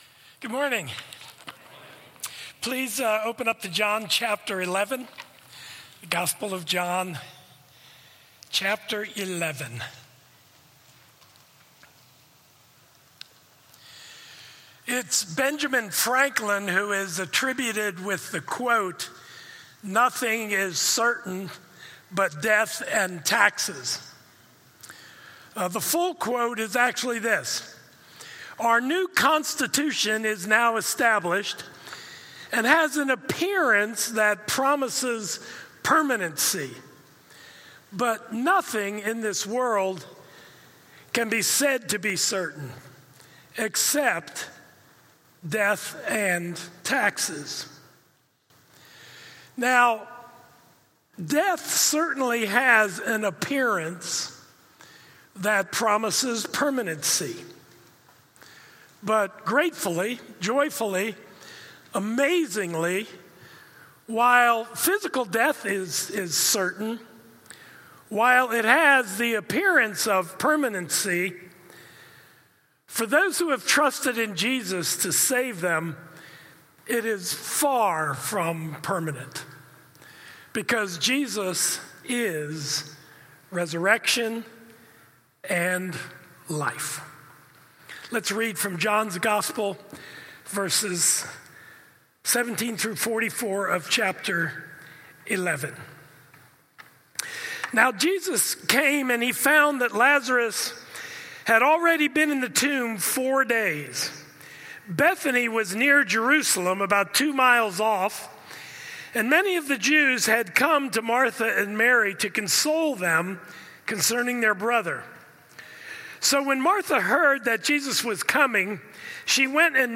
Jesus Is Resurrection & Life – Stand Alone Sermons | Crossway Community Church